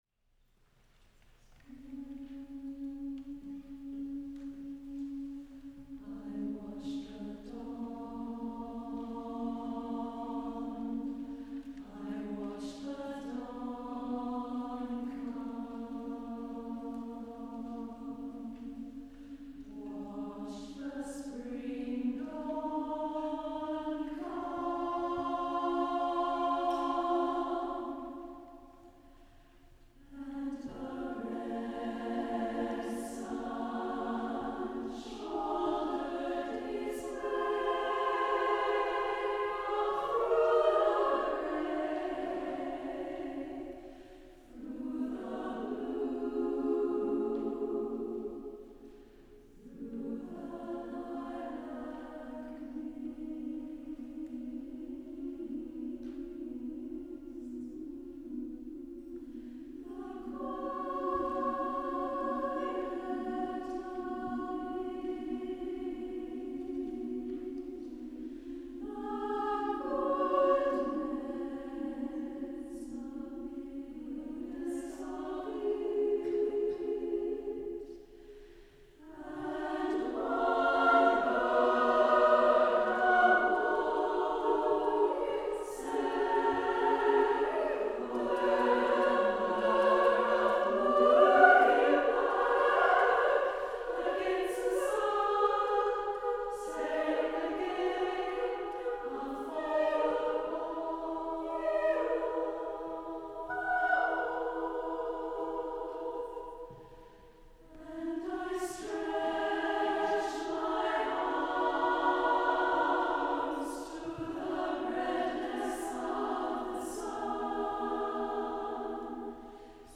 SSAA a cappella $2.50